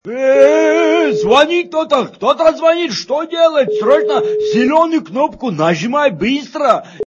» Звуки » Смешные » Звонит, кто-то звонит, что делать? - Нажимай зеленую кнопку, быстро!
При прослушивании Звонит, кто-то звонит, что делать? - Нажимай зеленую кнопку, быстро! качество понижено и присутствуют гудки.